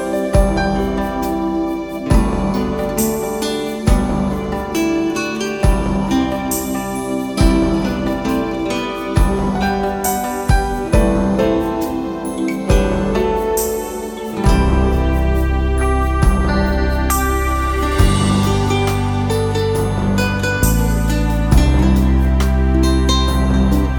Twofers Medley Medleys 4:52 Buy £1.50